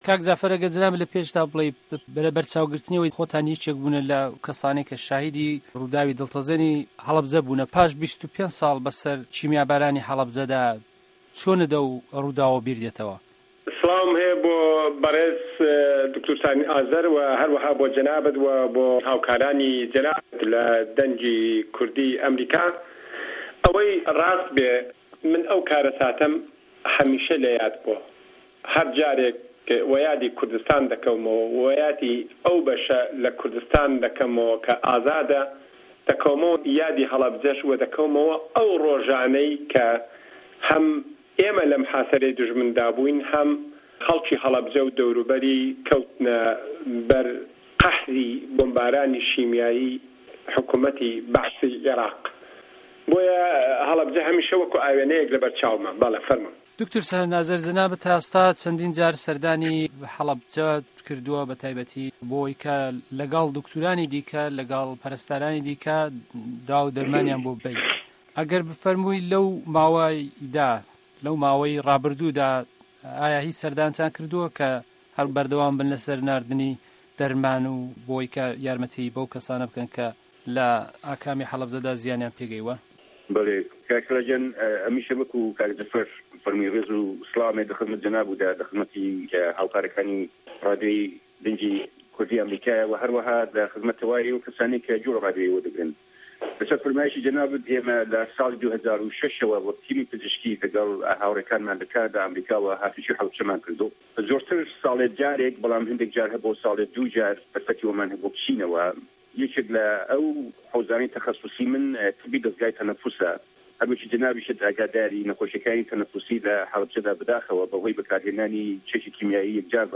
مێزگردێک سه‌باره‌ت به‌ کاره‌ساتی هه‌ڵه‌بجه‌